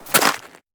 Sfx_creature_snowstalkerbaby_walk_01.ogg